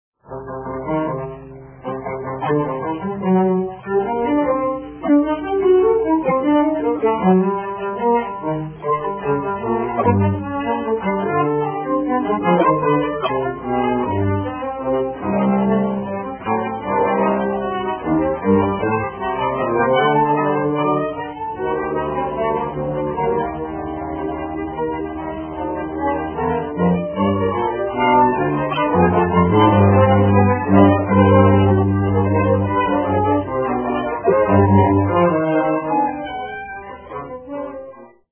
QUATUOR À CORDES